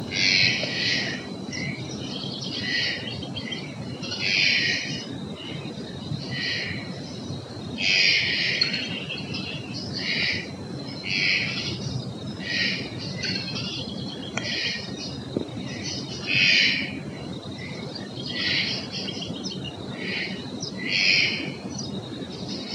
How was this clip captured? Location or protected area: Parque Nacional Iguazú Condition: Wild Certainty: Recorded vocal